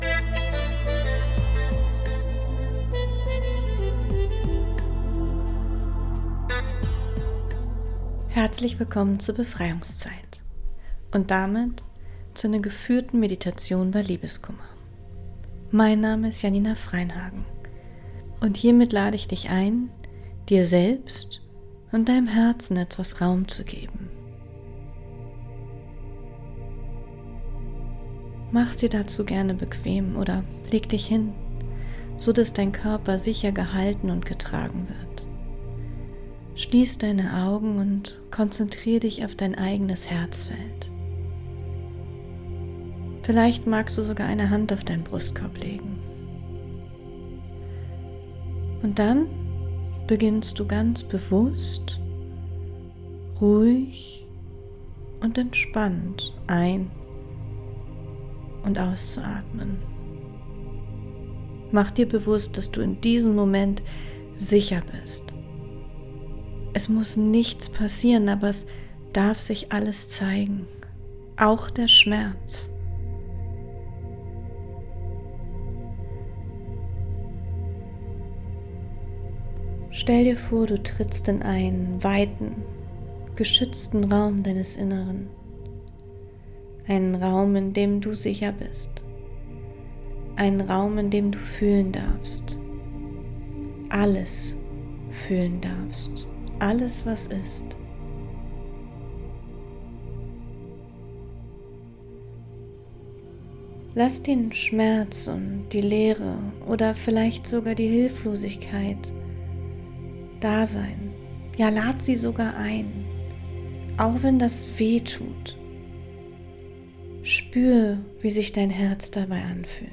Diese geführte Meditation begleitet dich durch den Schmerz und hilft dir, dich wieder mit deiner inneren Kraft, deinem Herzraum und deiner Selbstliebe zu verbinden.
Meditation_bei_Liebeskummer(1).mp3